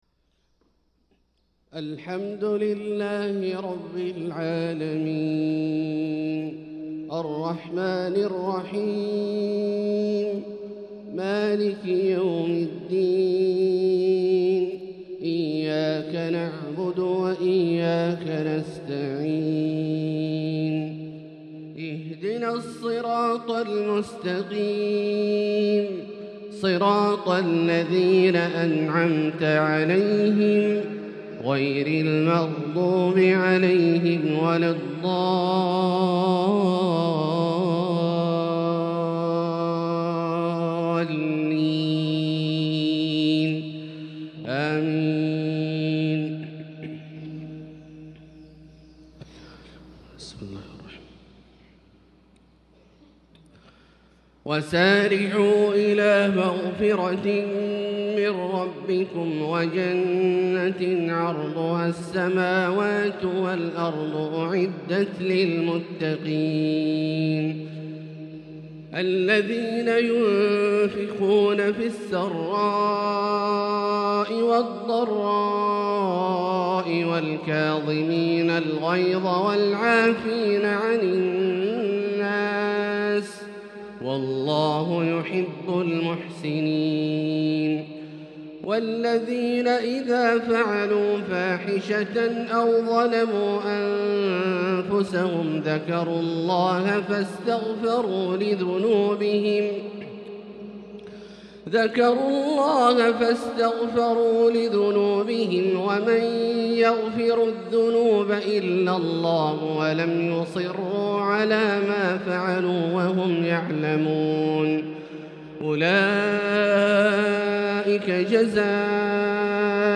Fajr prayer from Surah Aal-i-Imraan 6-2-2024 > 1445 H > Prayers - Abdullah Al-Juhani Recitations